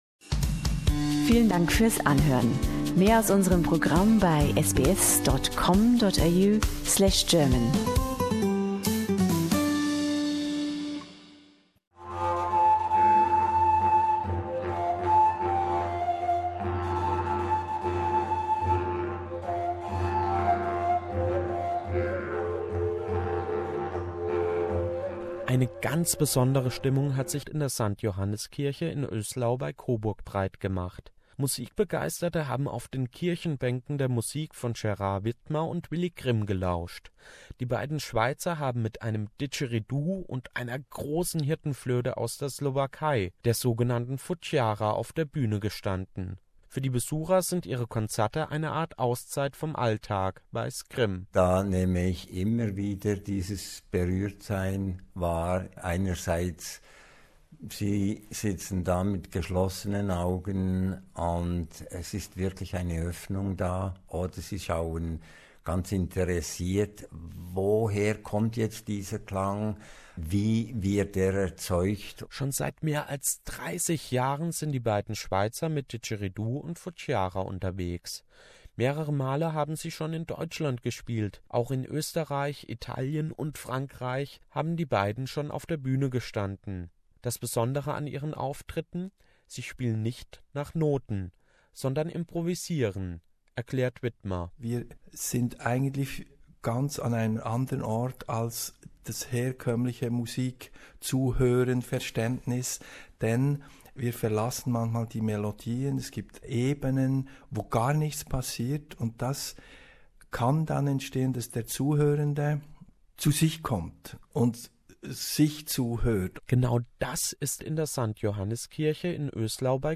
Didgeridoo music in a 15th century church in rural Bavaria - and the congregation loves it
Fujara